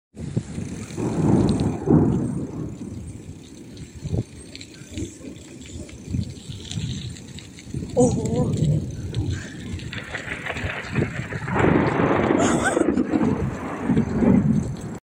ема гроза была, тут только начиналась а так после очень сильная была даже деревья падали..